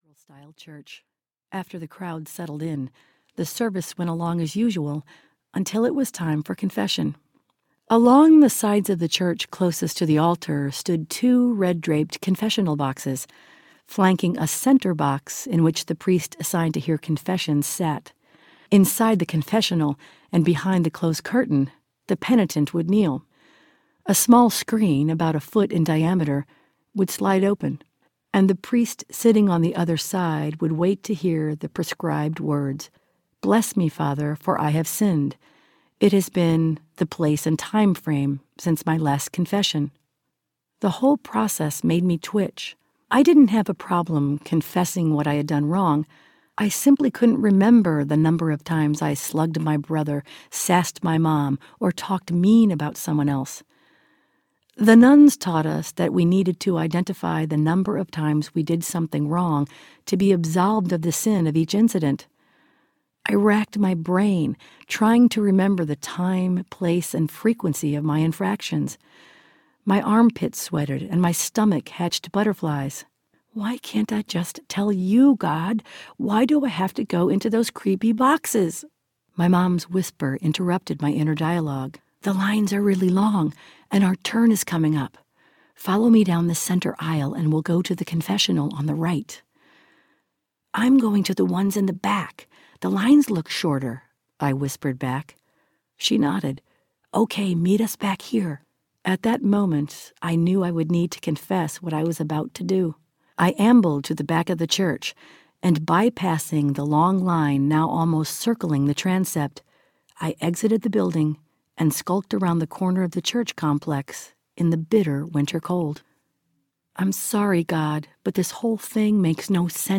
Minute by Minute Audiobook
Narrator
6.73 Hrs. – Unabridged